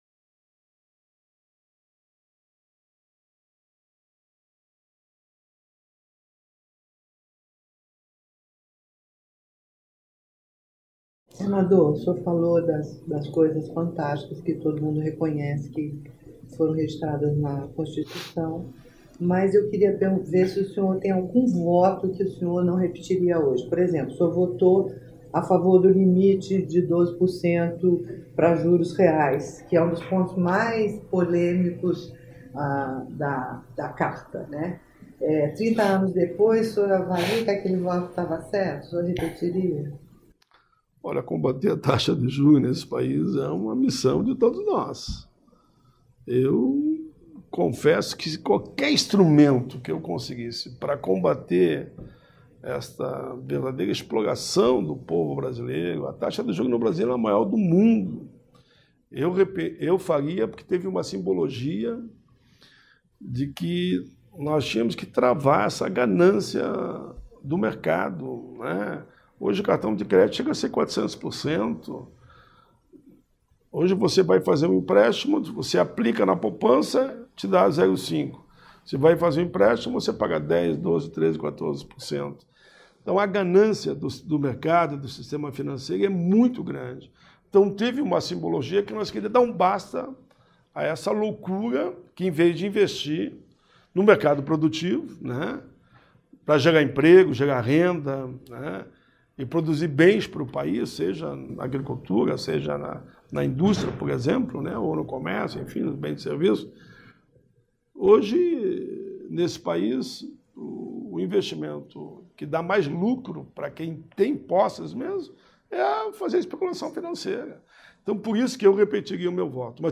entrevista-senador-paulo-paim-bloco-3.mp3